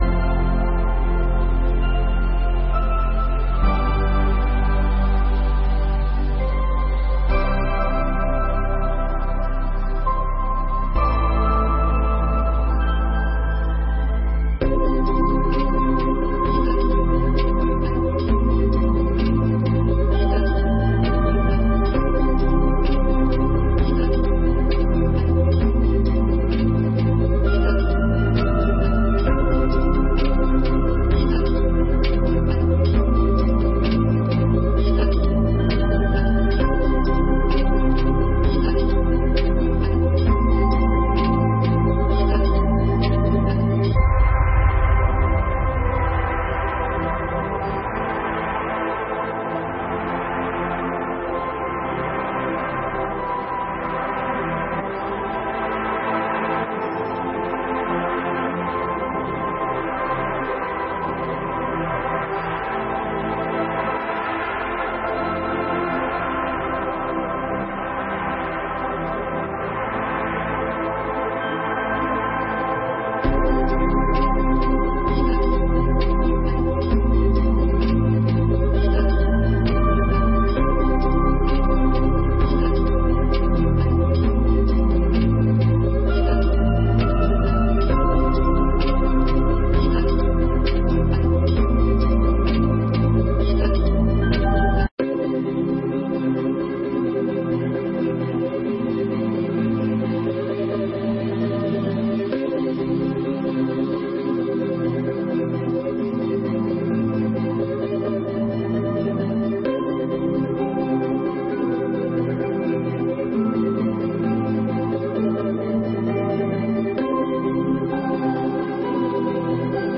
21ª Sessão Ordinária de 2021